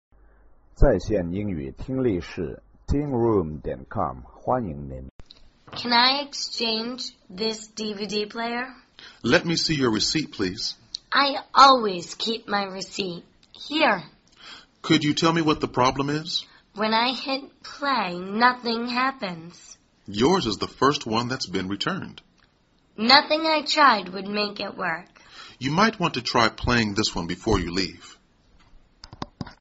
购物英语对话-Exchanging a DVD Player(4) 听力文件下载—在线英语听力室